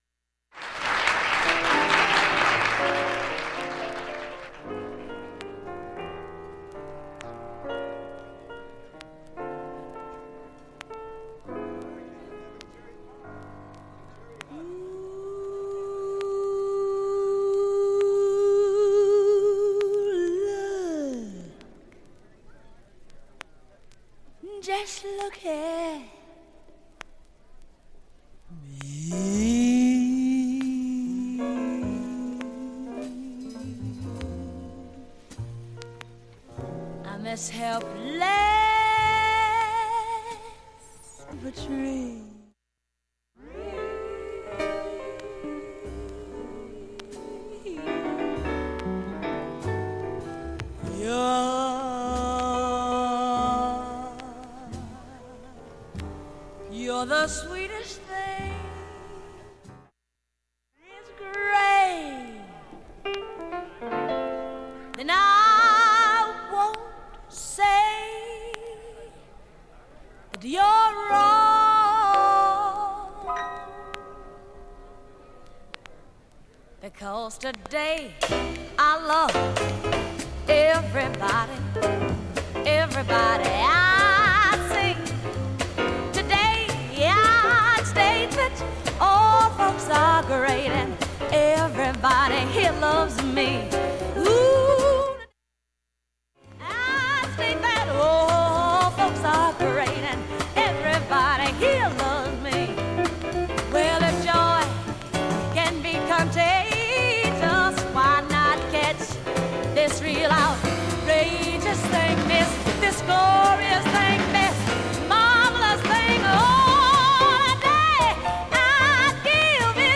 いわゆる無音部のチリも皆無で、
音質良好全曲試聴済み。
2,(43s〜)A-5中盤かすかなプツが７回
3,(1m00s〜)B-3中盤かすかなプツが１６回
◆ＵＳＡ盤オリジナル Mono
Recorded Live In New York February 10, 1965
ソウルフィーリングに溢れた圧倒的な